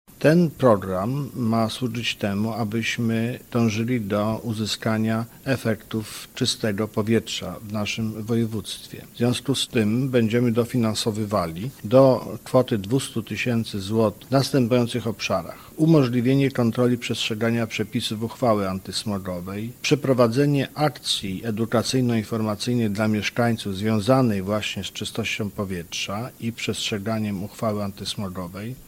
O potrzebie walki o czyste powietrze mówi marszałek woj. mazowieckiego Adam Struzik: